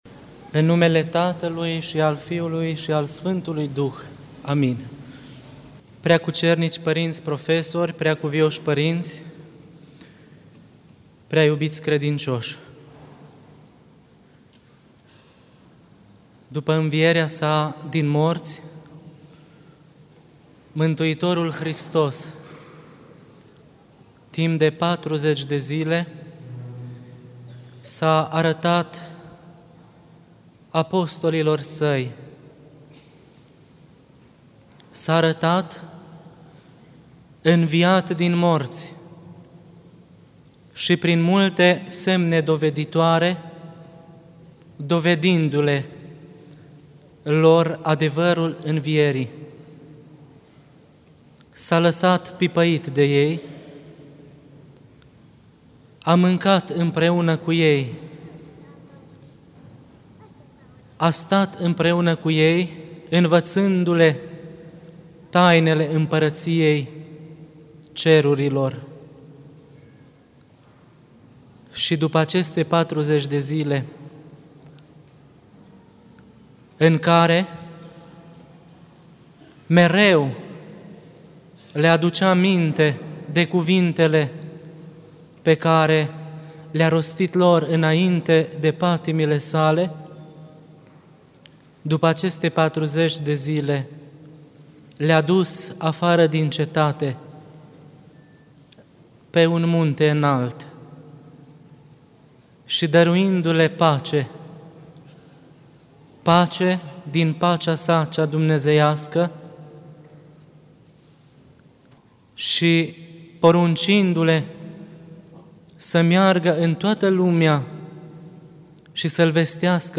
Predică la sărbătoarea Înălțării Domnului